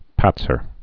(pătsər, pät-)